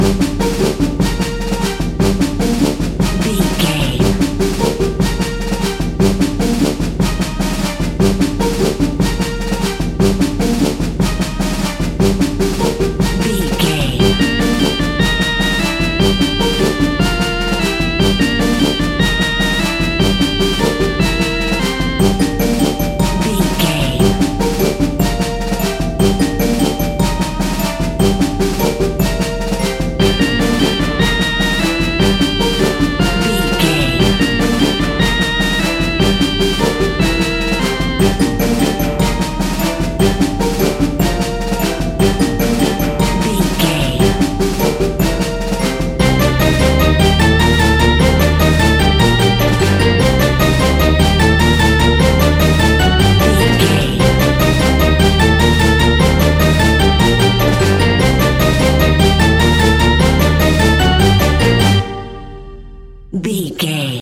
In-crescendo
Thriller
Aeolian/Minor
Fast
ominous
dark
dramatic
eerie
energetic
brass
synthesiser
drums
strings
instrumentals
horror music